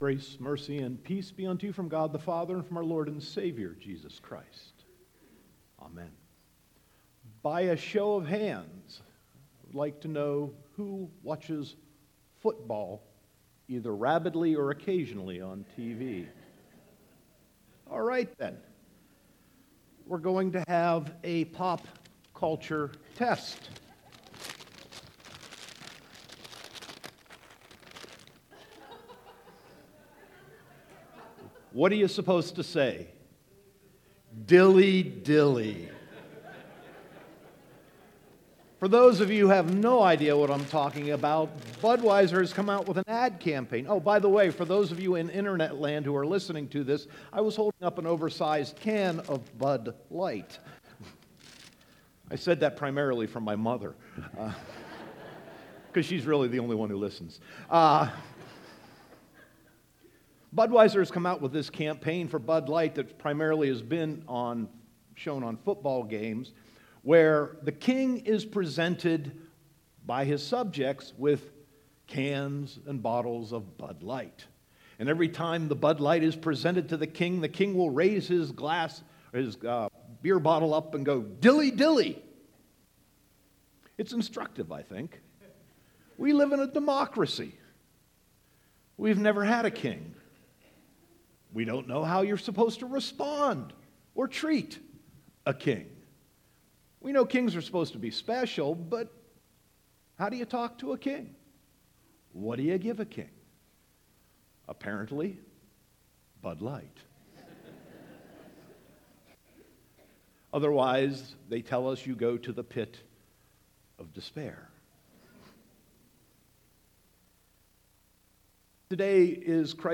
Sermon 11.26.2017